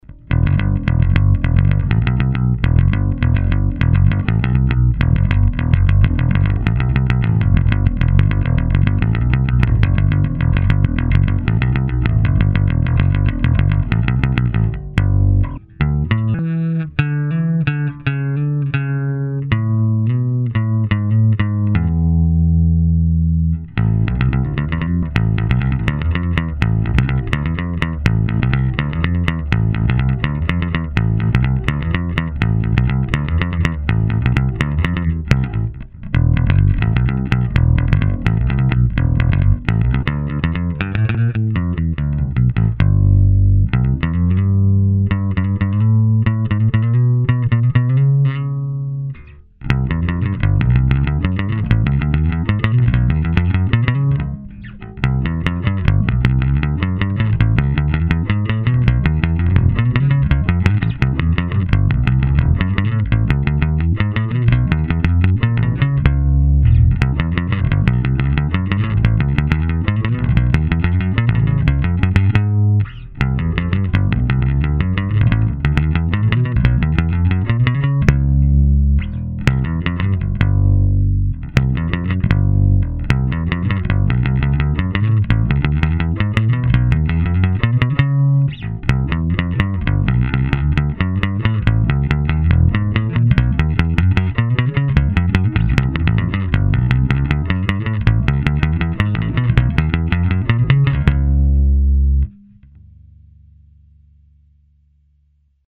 Já jsem si moji touhu po Rickenbackerovi ukojil nákupem Jolany D BASS a po úpravě, kdy z původní basy  zůstalo je dřevo a ještě ani to celé ne (hmatník je nový) to vypadá takto:
Zvuková ukázka - prsty